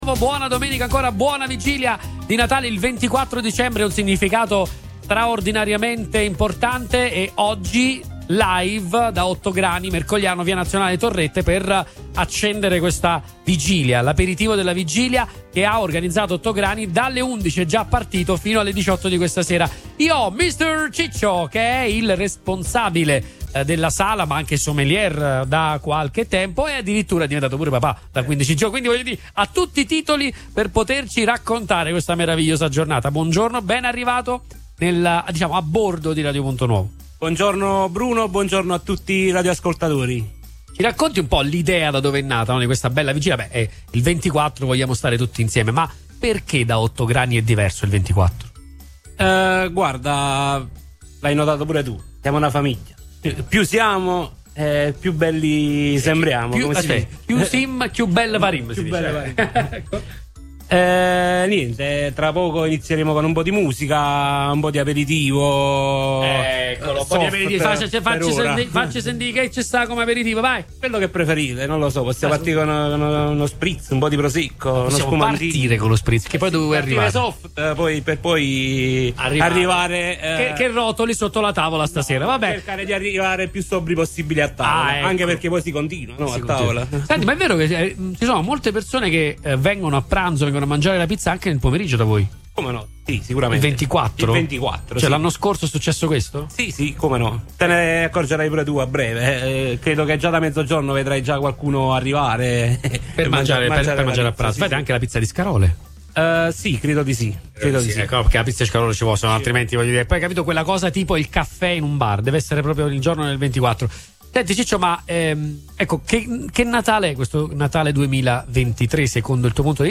L’atmosfera natalizia è stata resa ancora più speciale dalla diretta radiofonica che ha accompagnato gli aperitivi della vigilia, trasmettendo gioia e festa .